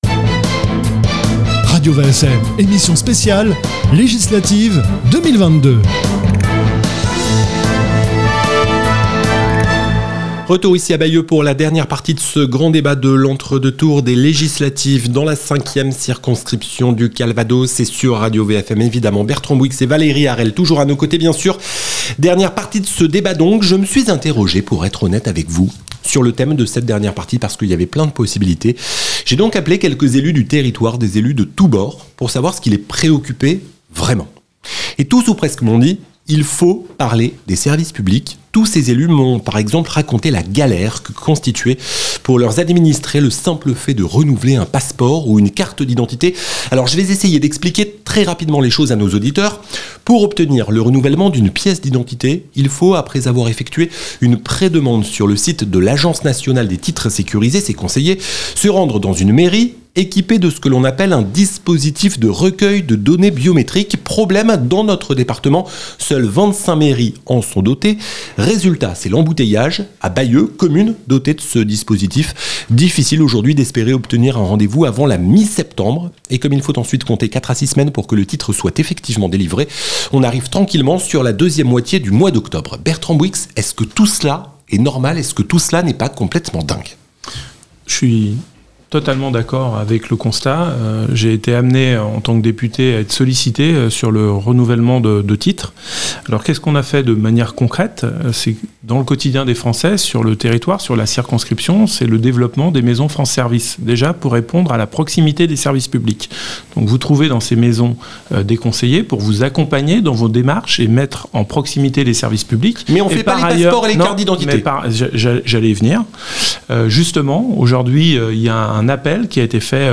Débat Législatives 2022-5ème Circonscription